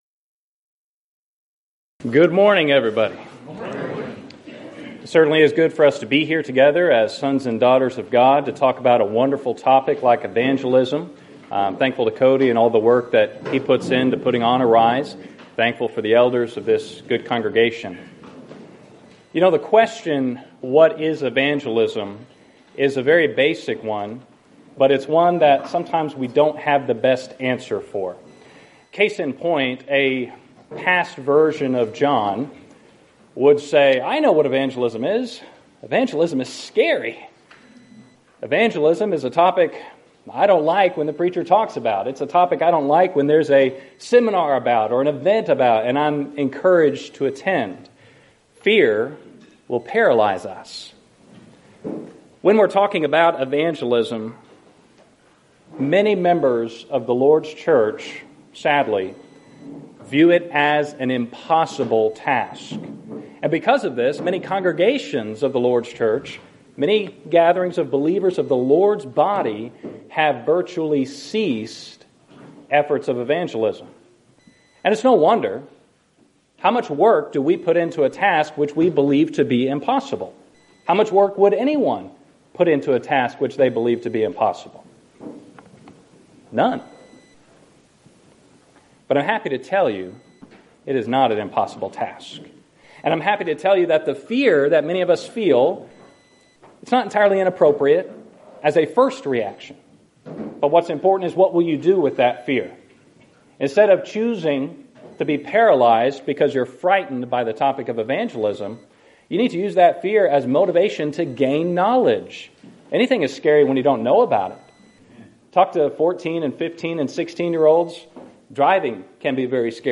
Event: 5th Annual Arise Workshop
lecture